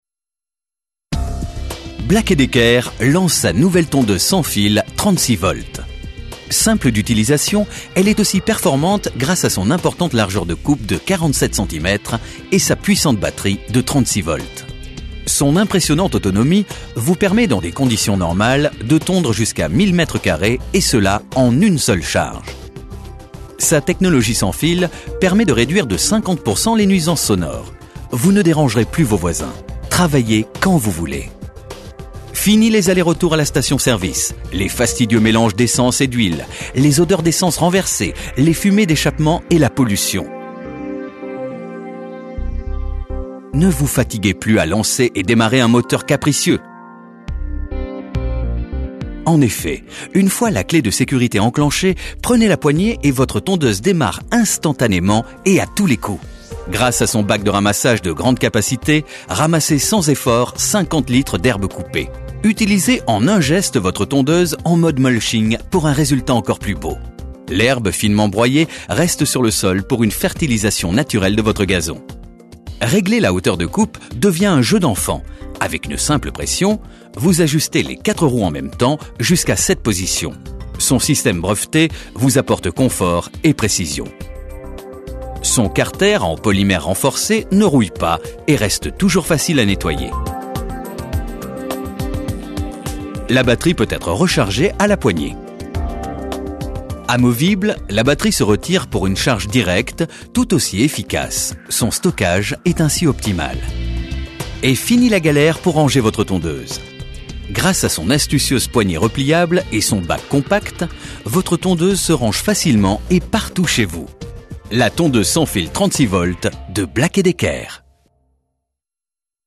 BLACK ET DECKER (voix présentation produit)